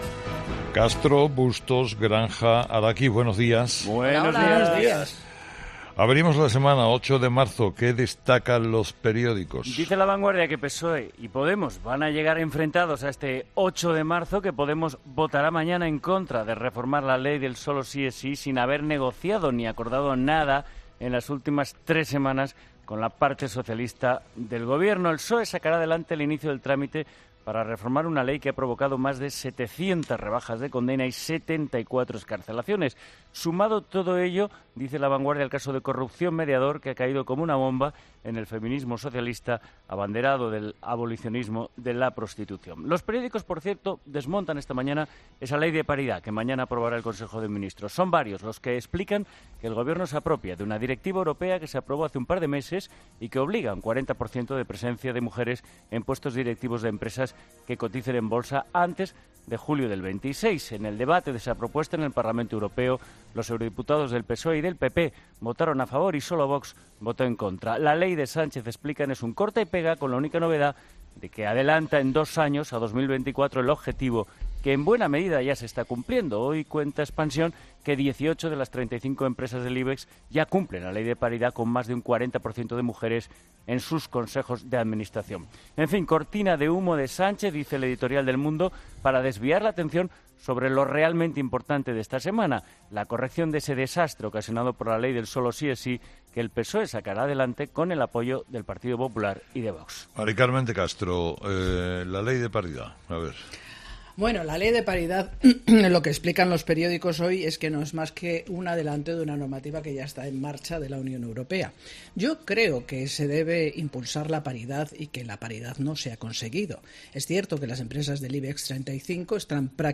Los colaboradores de 'Herrera en COPE' analizan el verdadero sentido de la ley de paridad que impulsa, ahora, el presidente del Gobierno
La ley de paridad, a debate en el 'Café de redacción' de 'Herrera en COPE'